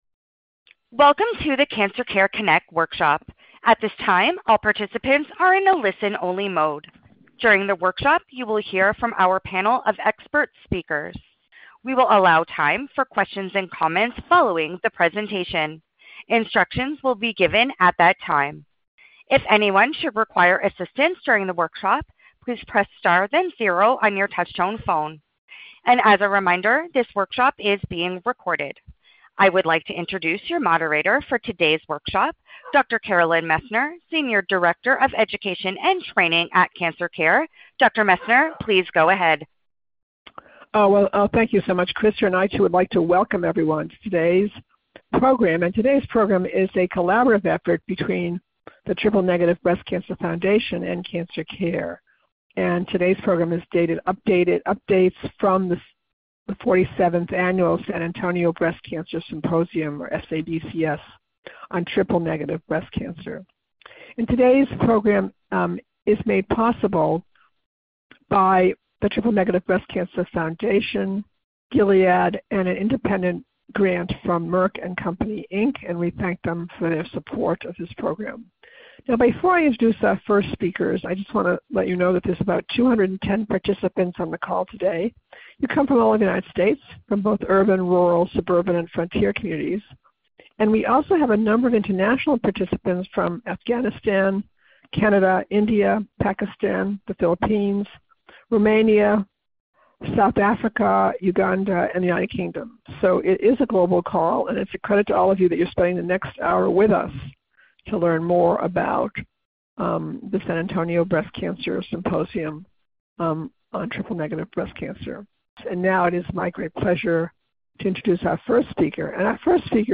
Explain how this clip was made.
This workshop was originally recorded on January 07, 2025.